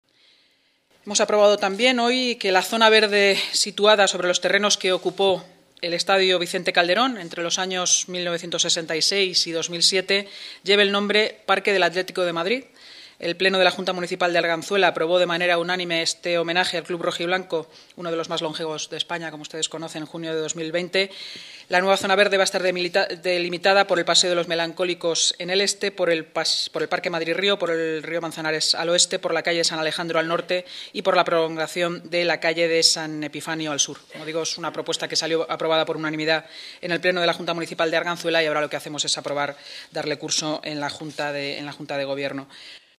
Nueva ventana:Así lo ha explicado la portavoz municipal, Inmaculada Sanz, tras la celebración de la Junta de Gobierno: